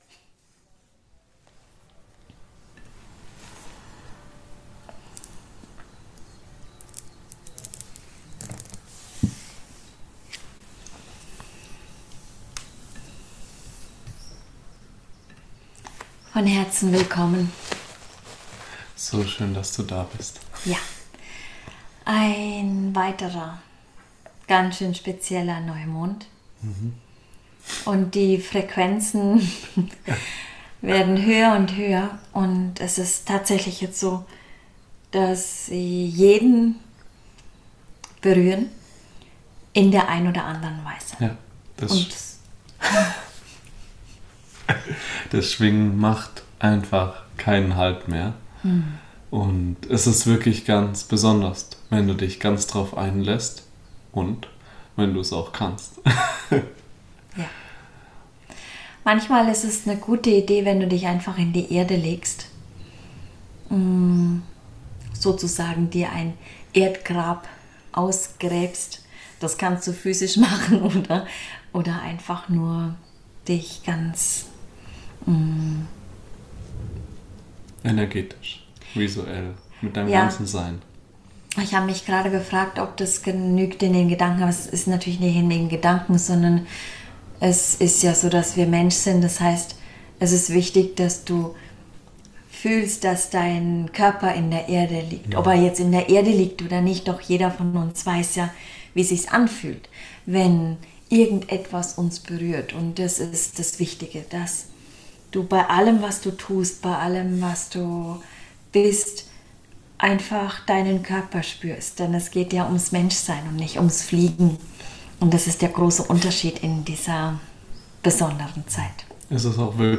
108 Oms für dich.